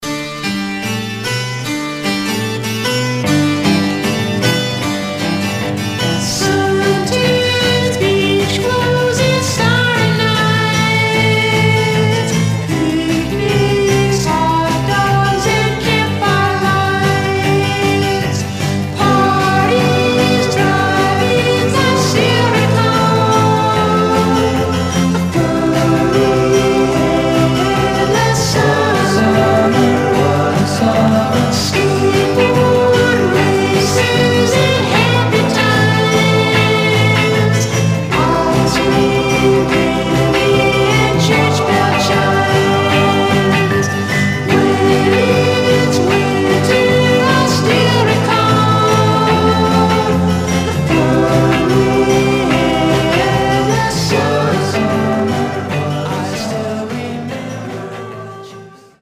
Like Beachboys Condition: M- DJ
Mono
Surf